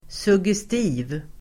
Ladda ner uttalet
suggestiv adjektiv, suggestive Uttal: [s'ug:esti:v (el. -'i:v)] Böjningar: suggestivt, suggestiva Synonymer: förförisk, påverkande Definition: starkt själsligt påverkande Exempel: en suggestiv stämma (a suggestive atmosphere)